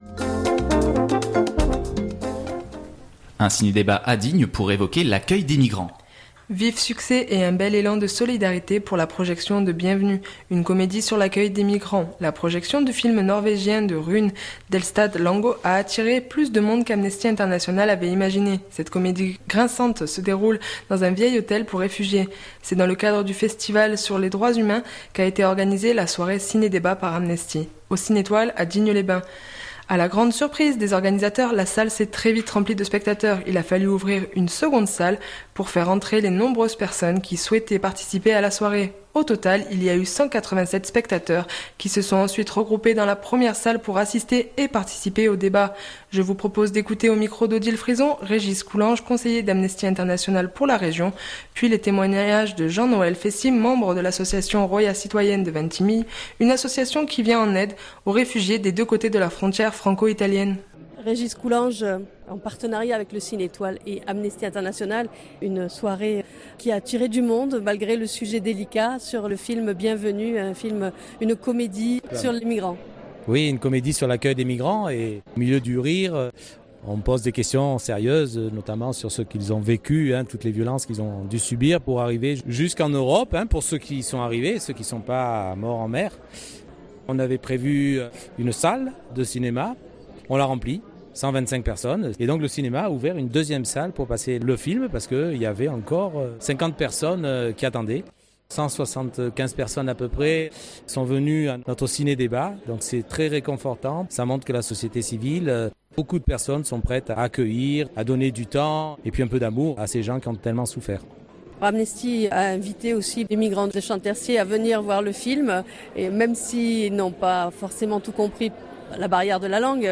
C’est dans le cadre du Festival du film sur les droits humains qu’a été organisée la soirée ciné-débat par Amnesty au cinétoile à Digne-les-Bains.